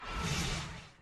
added sound effects
sound_shutdown.mp3